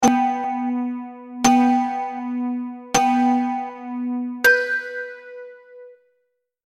دانلود آهنگ ساعت 15 از افکت صوتی اشیاء
جلوه های صوتی
دانلود صدای ساعت 15 از ساعد نیوز با لینک مستقیم و کیفیت بالا